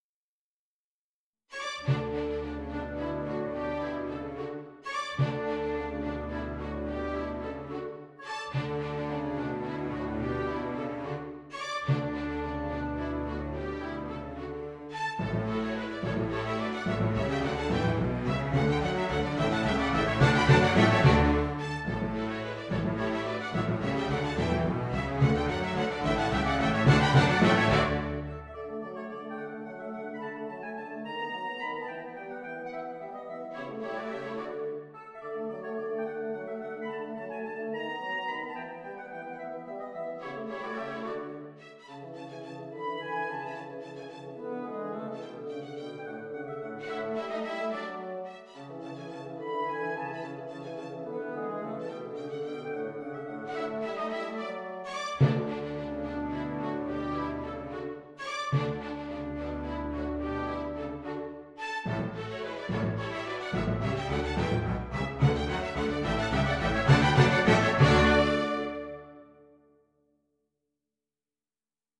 I did now the same with the first 5 dances on the basis of these old midi files by simplifying them a bit and using my tool NotePerformer.
My renditions may have too much reverb, should I reduce the hall effect?
WoO13-4_orch.mp3